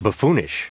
Prononciation du mot buffoonish en anglais (fichier audio)
Prononciation du mot : buffoonish